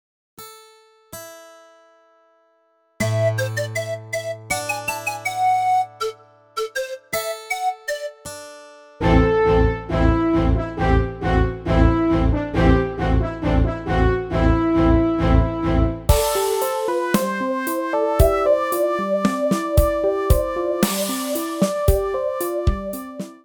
A short demo of instruments from the Yamaha S90.
YamahaS90Demo.ogg.mp3